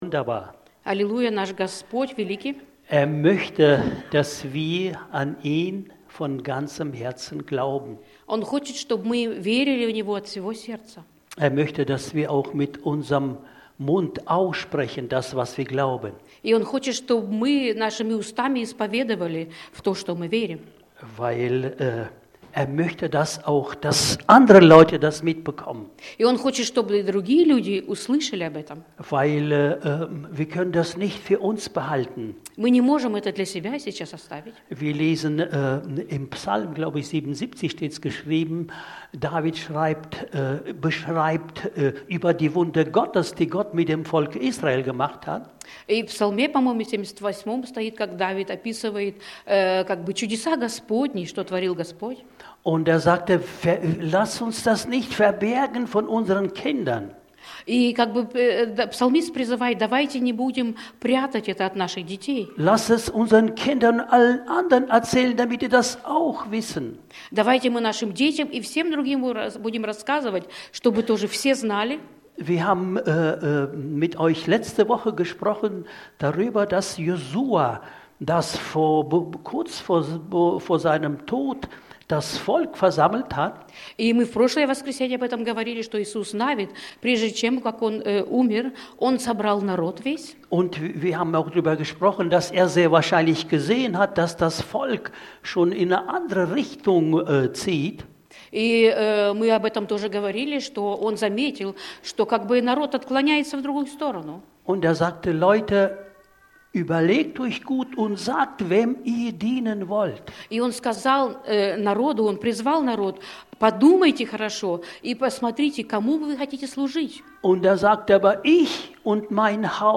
Predigten – Freie Evangeliums Christengemeinde Löningen